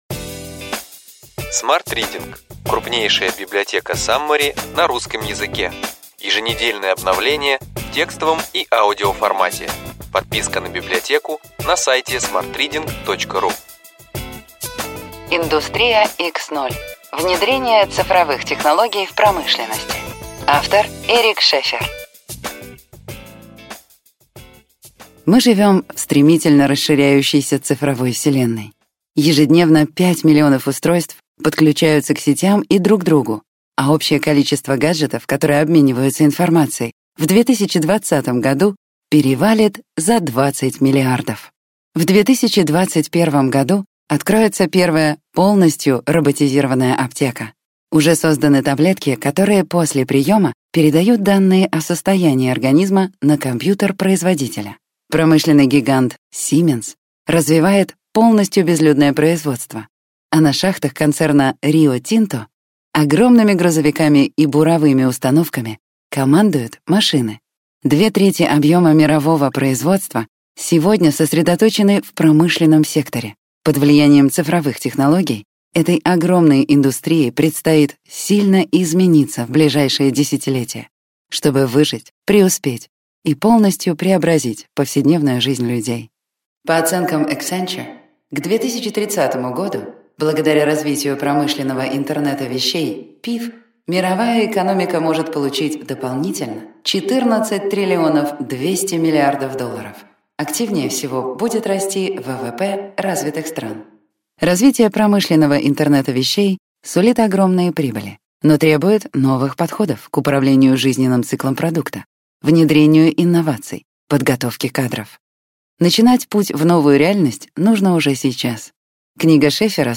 Аудиокнига Ключевые идеи книги: Индустрия X.0. Внедрение цифровых технологий в промышленности.